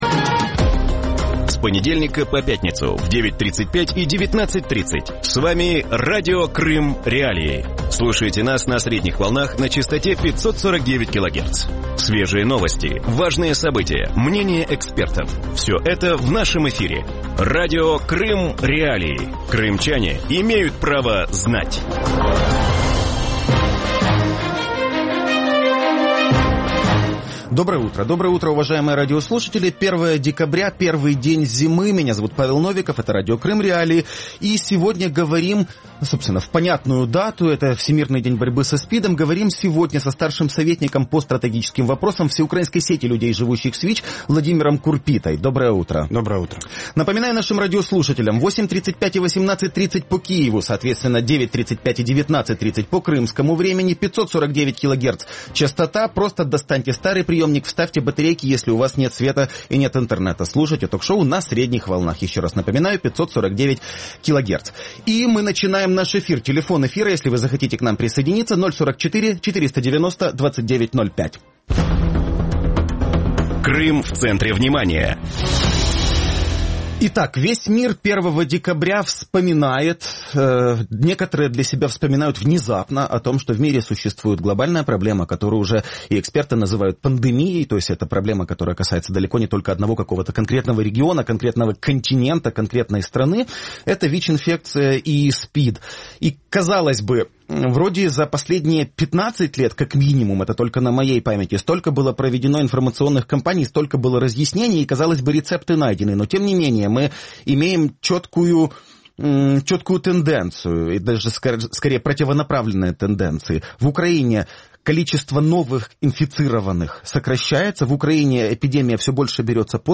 В утреннем эфире Радио Крым.Реалии во Всемирный день борьбы со СПИДом выясняют, почему в России растет эпидемия ВИЧ/СПИДа и чем это грозит для Крыма.